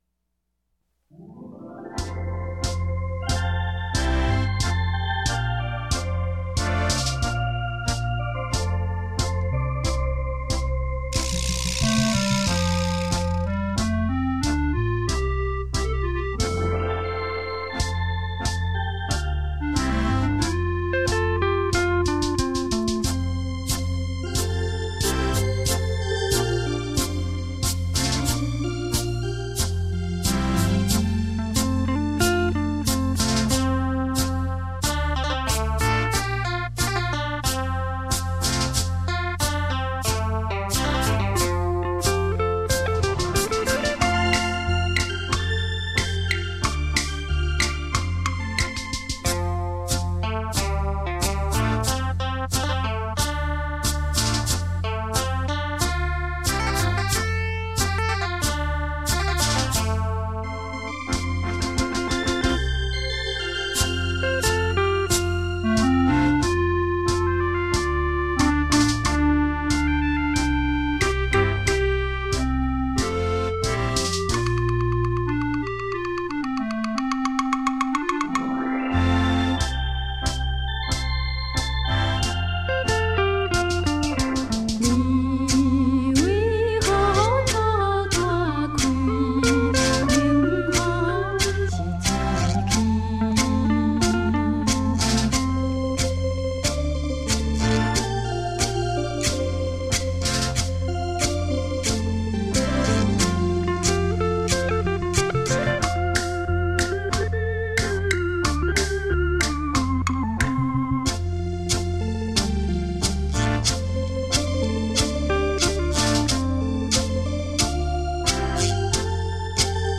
原音重现·超立体现场演奏